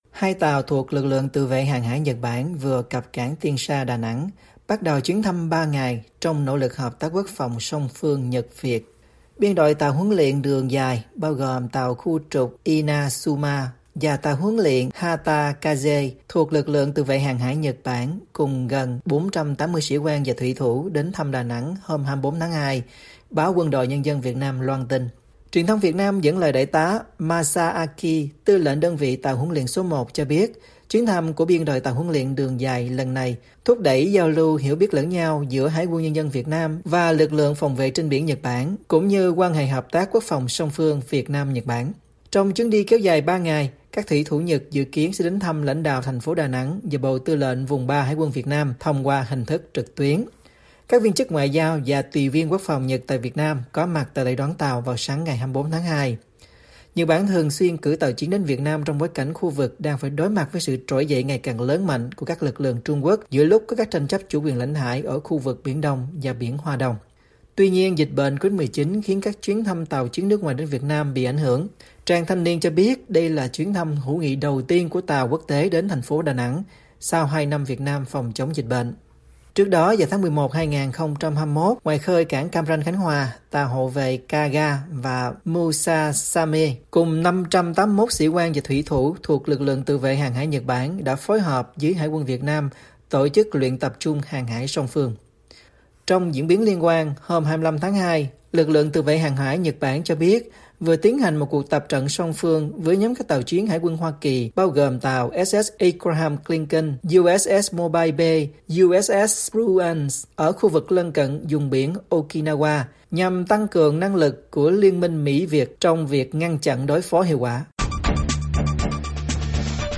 Người Việt ở Ukraine lo lắng và ‘căm thù’ Putin - Điểm tin VOA